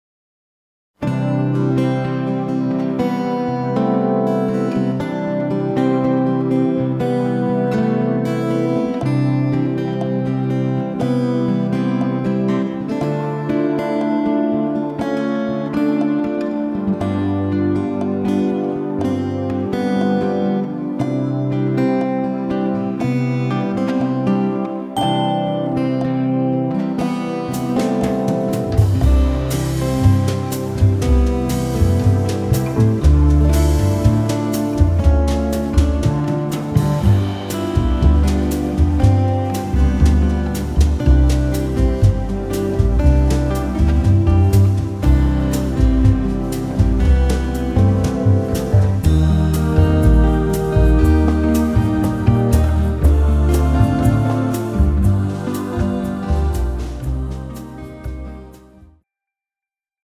bossanova style
tempo 120 bpm
key C
Female singers backing track